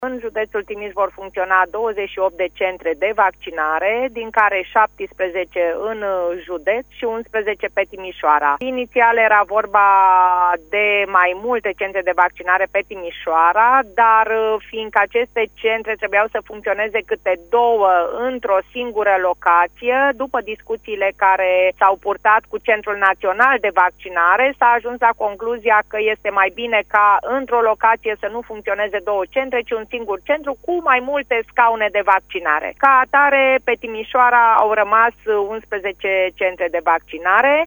Numărul centrelor de vaccinare anti-COVID ce vor funcționa la Timișoara a fost redus, anunță prefectul de Timiș. Liliana Oneț a precizat, la Radio Timișoara, că, din motive tehnice, în orașul de pe Bega vor fi amenajate doar 11 centre, față de 17, cât se anunța inițial.